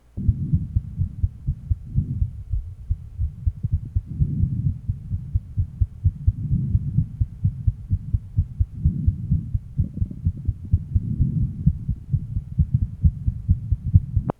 Total Anomalous Pulmonary Venous Return
Date 1969 Type Systolic Abnormality Total Anomalous Pulmonary Venous Return Double outlet RV, pulmonary stenosis, total anomalous venous return To listen, click on the link below.
RUSB (Channel A) LUSB (Channel B) LLSB (Channel C) Apex (Channel D) %s1 / %s2